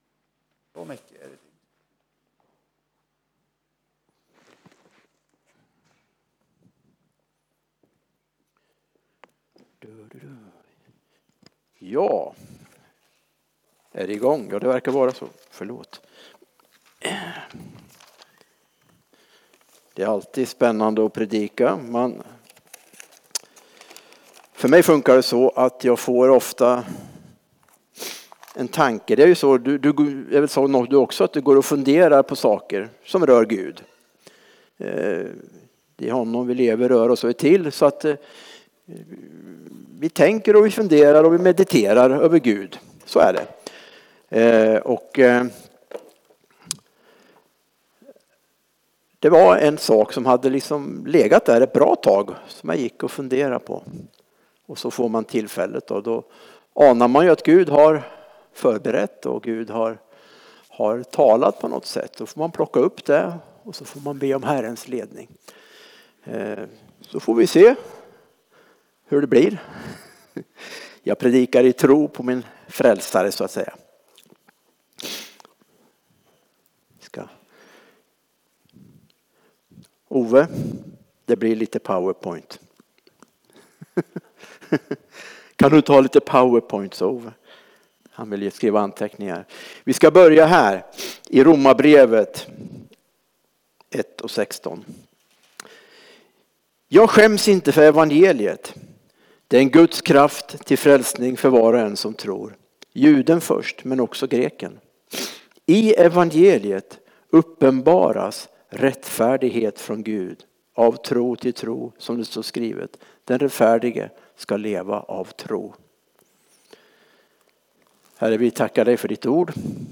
Furuhöjdskyrkans församling, Alunda
Gudstjänst 16 februari